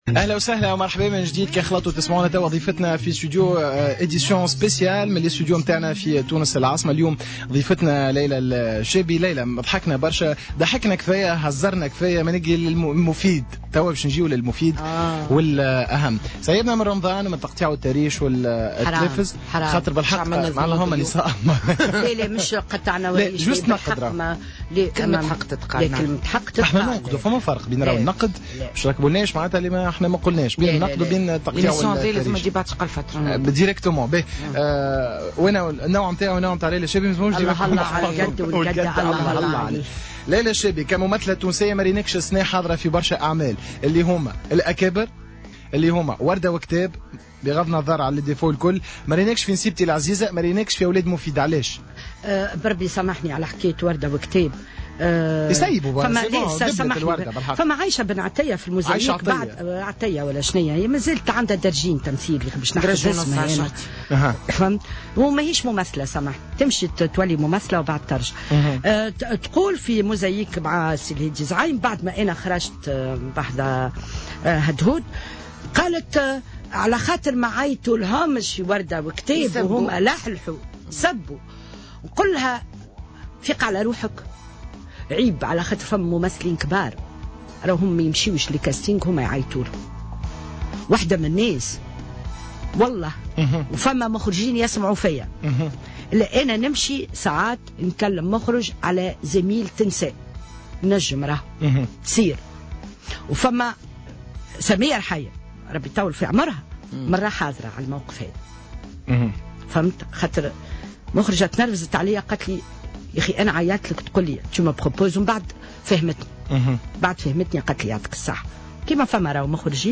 انتقدت الممثلة ليلى الشابي ضيفة الجوهرة "اف ام" اليوم الأربعاء 22 جوان 2016 أداء الممثلة الشابة عائشة عطية مضيفة أنها ليست ممثلة وعليها أن تتعلم التمثيل ثم تعود على حد قولها.